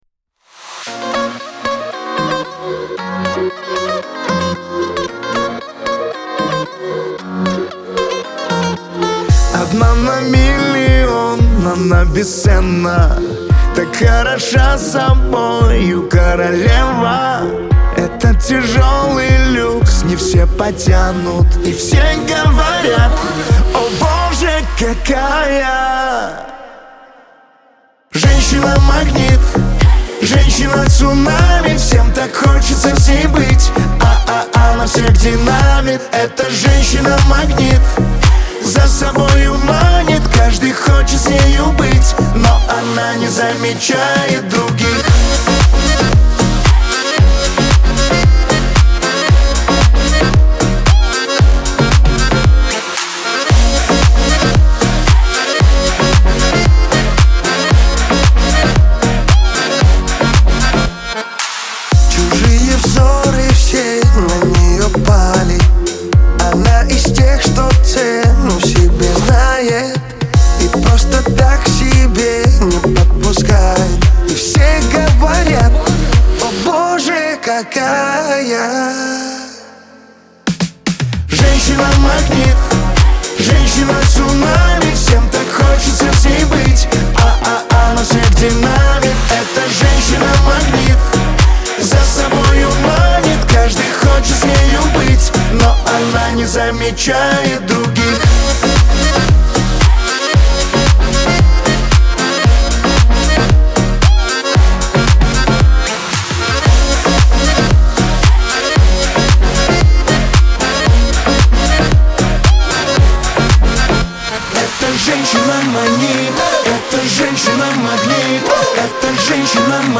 дуэт
танцевальная музыка , pop , Веселая музыка , эстрада